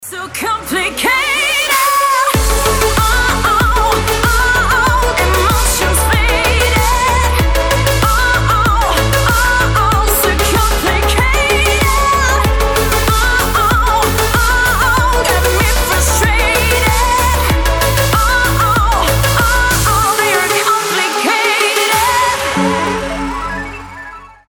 • Качество: 320, Stereo
поп
громкие
женский вокал
Electronic
сильный голос